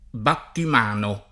battim#no], inv.